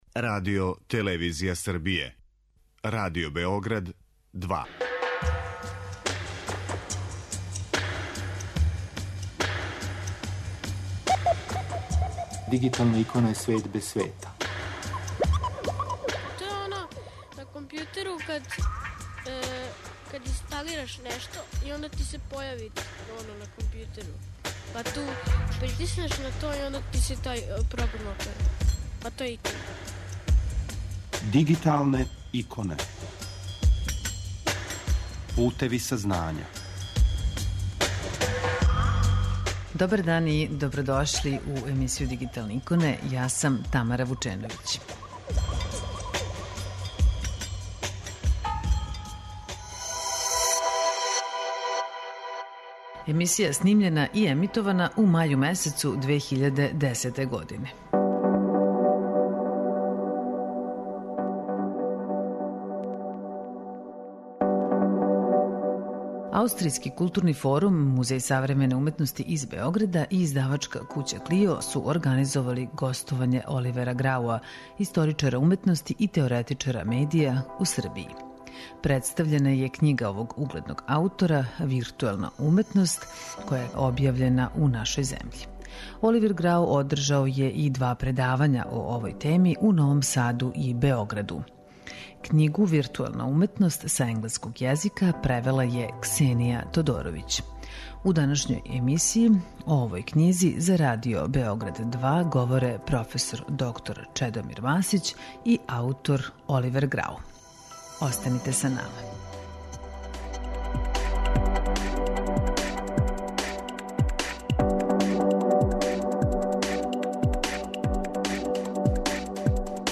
Слушамо репризу емисије која је том приликом снимљена и емитована.